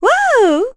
Laudia-Vox_Happy4_kr.wav